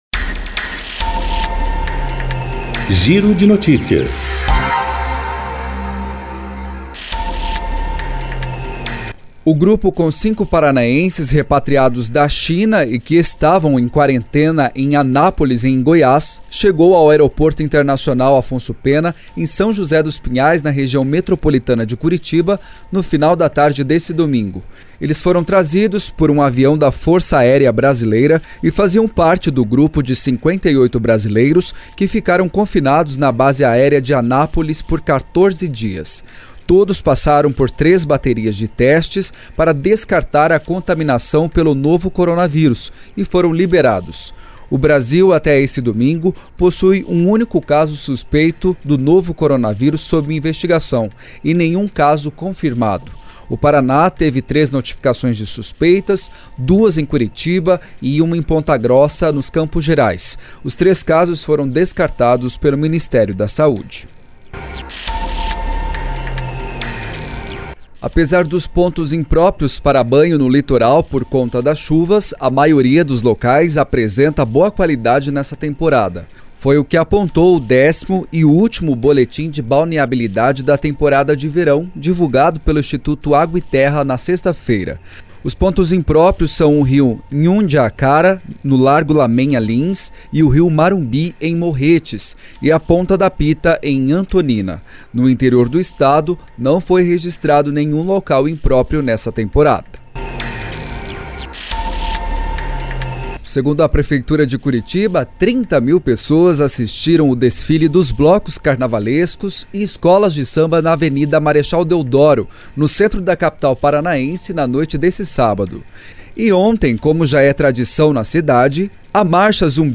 Giro de Notícias COM TRILHA